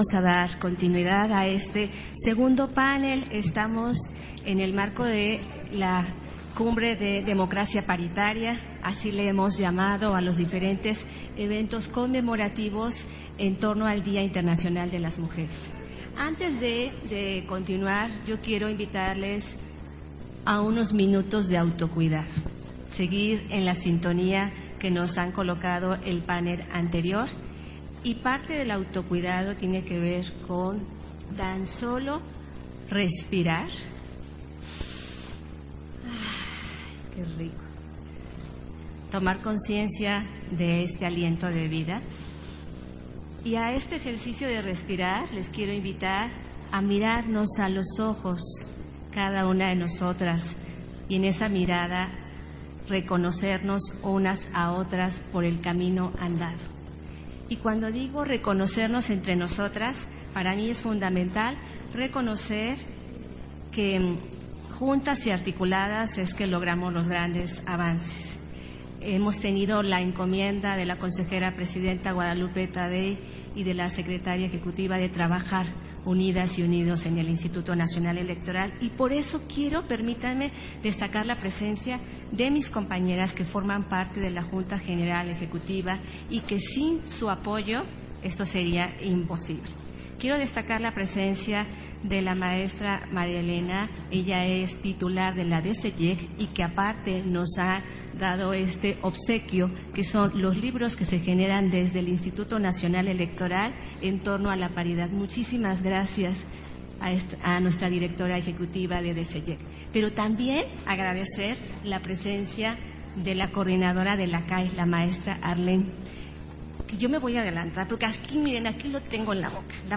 Panel 2, La paridad en el ejercicio del poder, en el marco del Diálogo entre mujeres a una década de la paridad en México